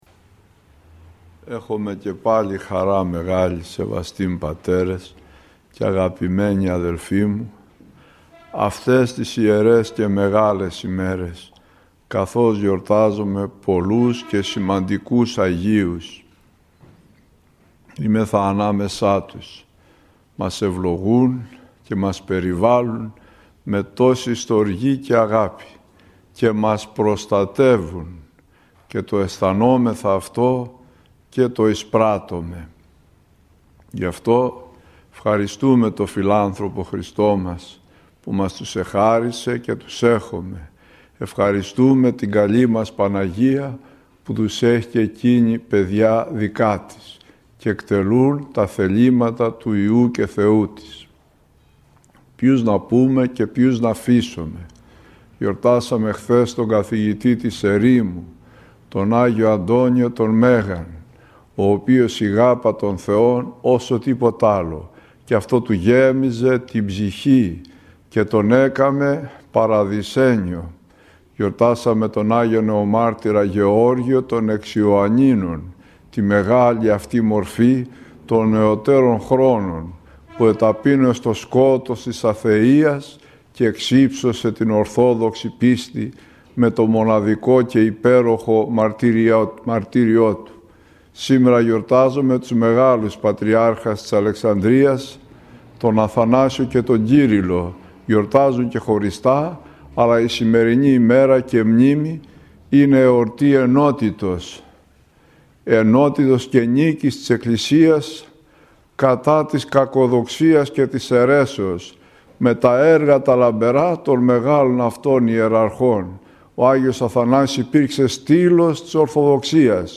20 Ιανουαρίου, μνήμη και του Αγίου Νεομάρτυρος Ζαχαρίου του εν παλαιαίς Πάτραις Μαρτυρήσαντος: Βίος, Ακολουθία, ηχογραφημένη ομιλία του Αρχιν.